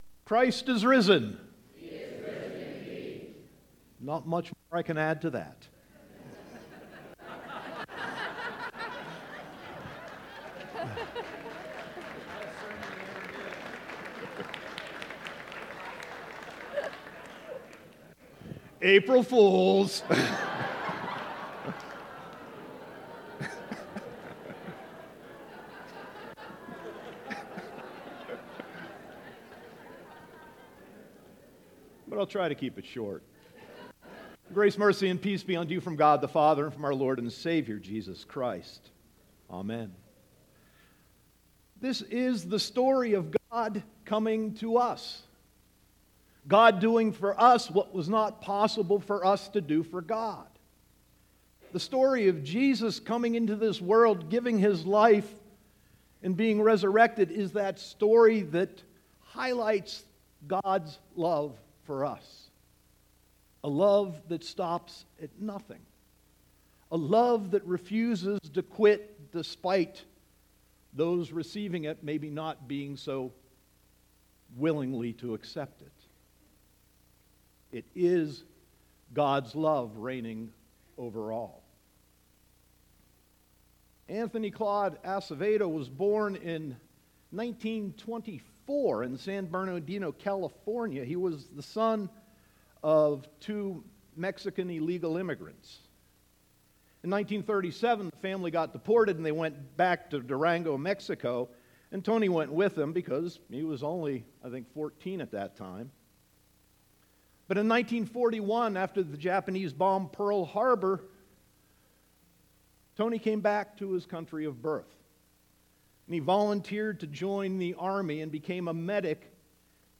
Sermon 4.1.2018